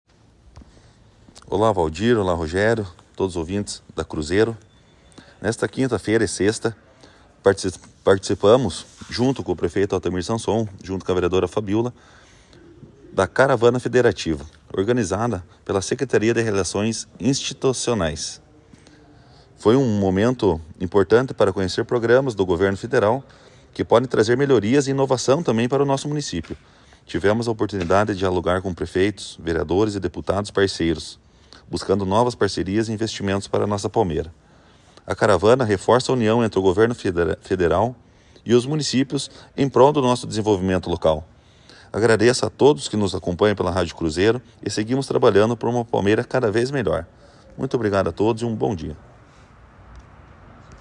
O presidente da Câmara Municipal, vereador Diego Zanetti (Republicanos), falou com a Rádio Cruzeiro diretamente de Foz do Iguaçu, onde participa da Caravana Federativa ao lado do prefeito Altamir Sanson (PSD) e da vereadora Fabiola Mereles (PP).
Em entrevista ao jornalismo da emissora, Zanetti destacou a importância do evento, que promove a aproximação entre os municípios e o Governo Federal, facilitando o acesso direto a programas, serviços e orientações dos ministérios e órgãos federais voltados ao desenvolvimento local e regional.